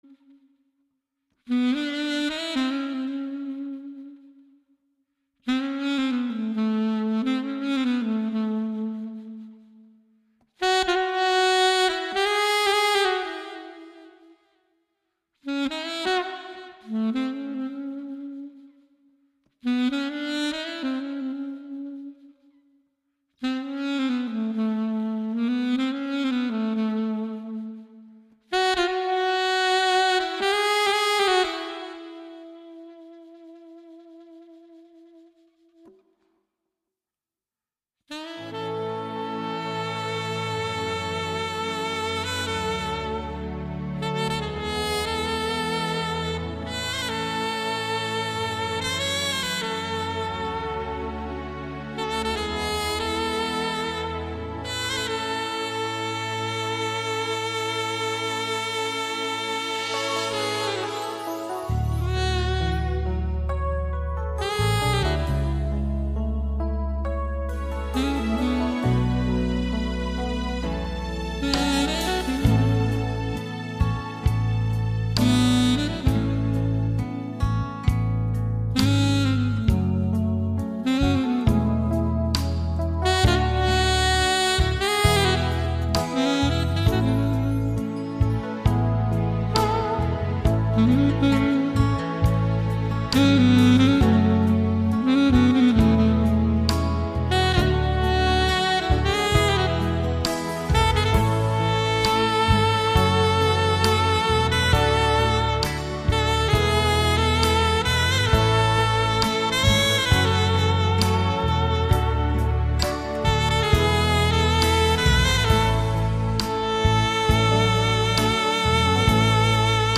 Viento Madera
SAXOFÓN CONTRALTO
sax-alto.mp3